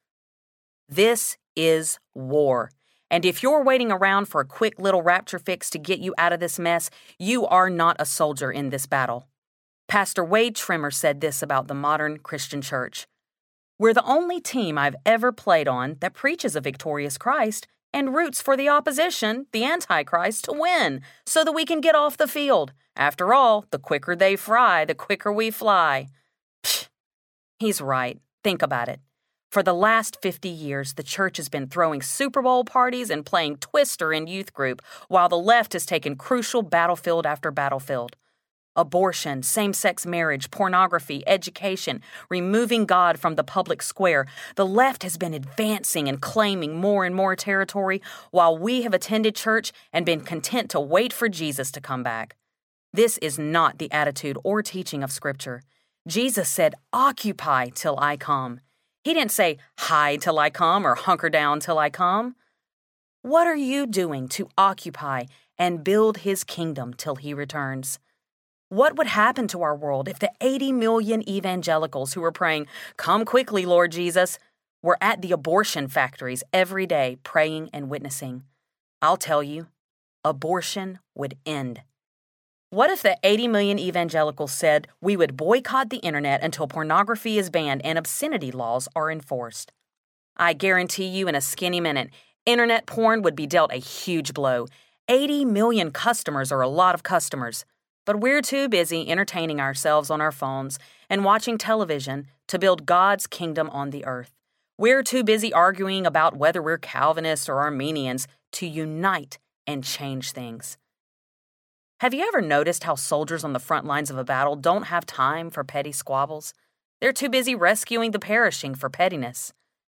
Not on My Watch Audiobook
Narrator